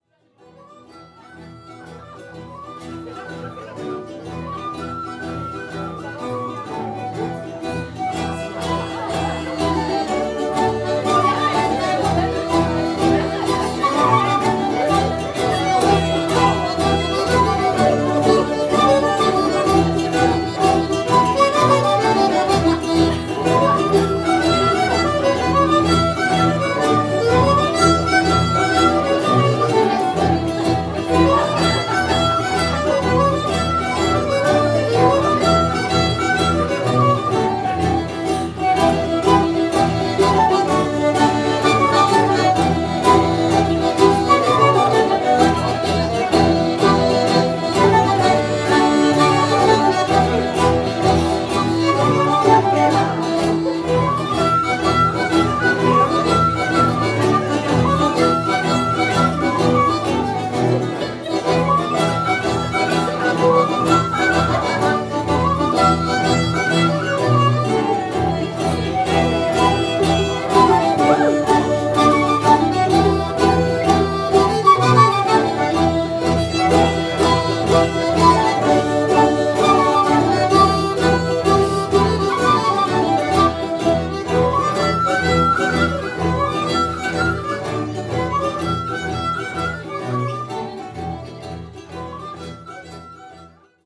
Bal Folk à l’Imprimerie Café-Théâtre de Rive-de-Gier, le 1er décembre 2024